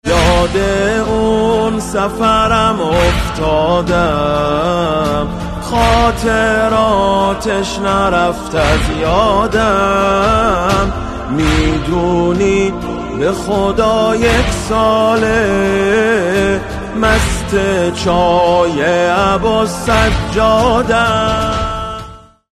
زنگ موبایل (باکلام) حماسی و محزون